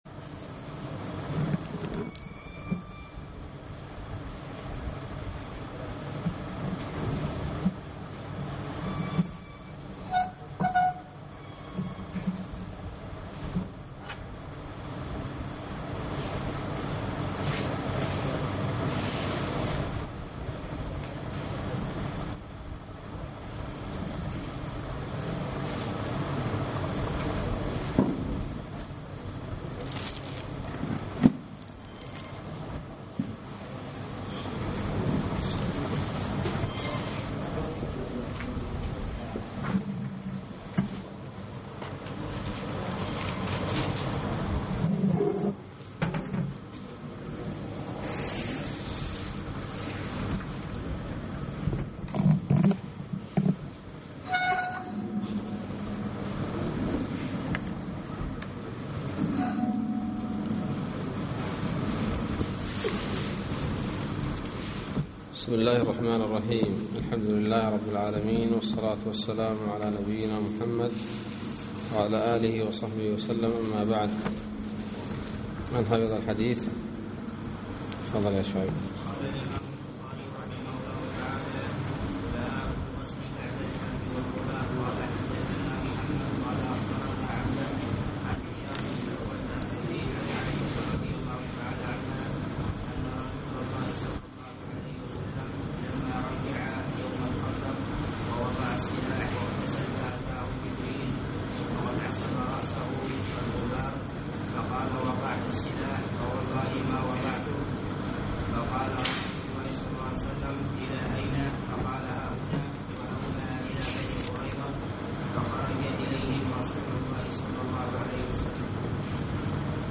الدرس الواحد والعشرون من كتاب الجهاد والسير من صحيح الإمام البخاري